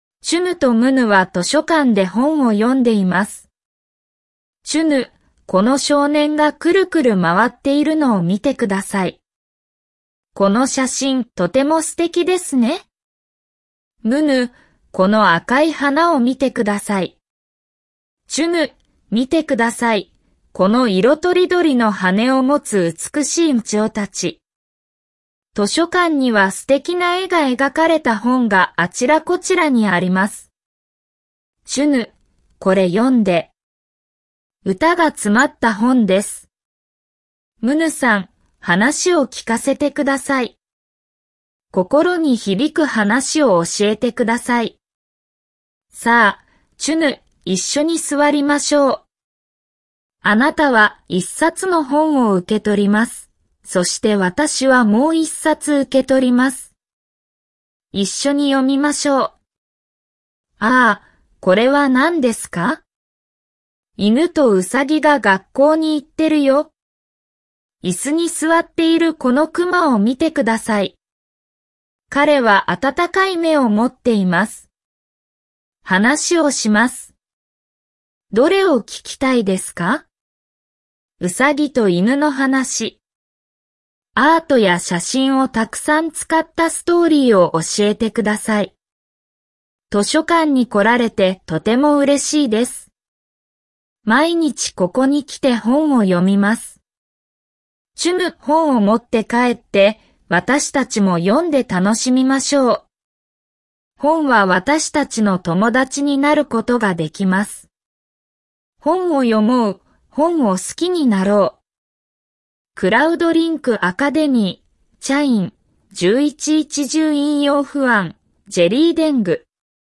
125_FKB-Stories-Chunu-and-Munu-Read_ja.mp3